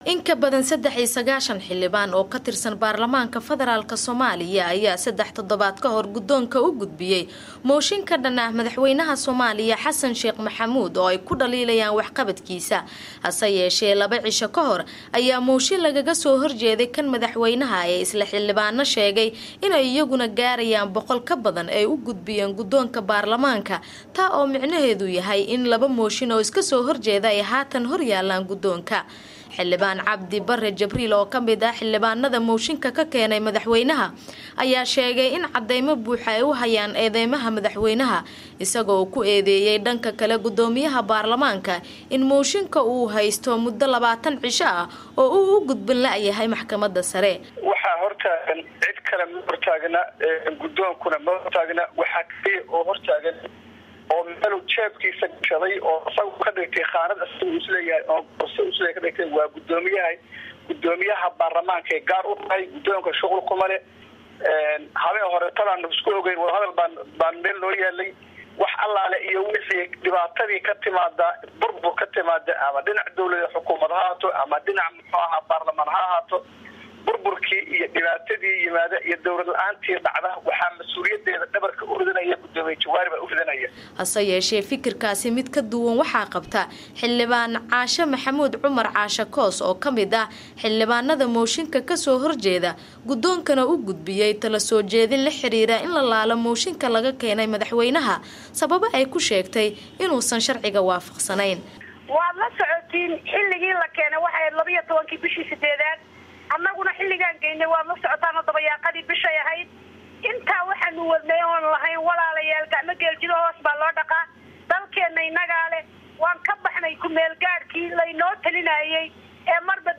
Warbixin: Xiisadda Mooshinka